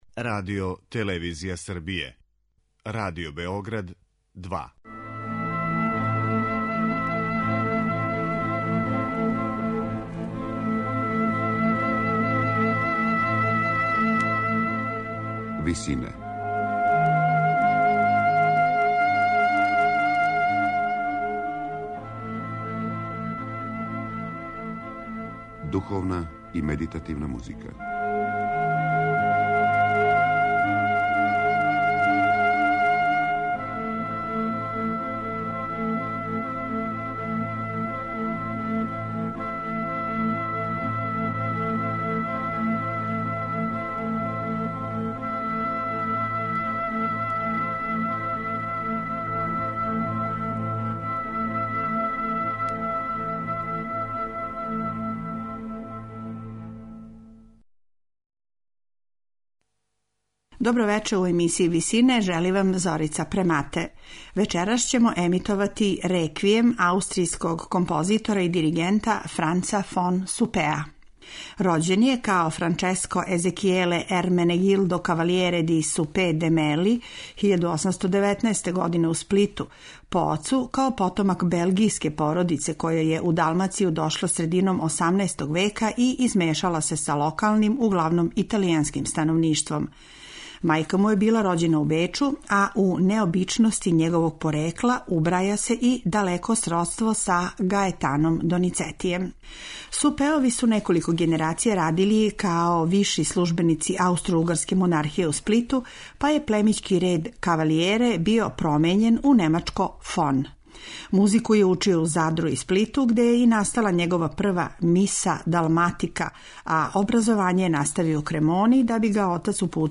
Дело ћемо емитовати са концертног снимка
сопран
мецосопран
тенор
бас.
у ВИСИНАМА представљамо медитативне и духовне композиције аутора свих конфесија и епоха.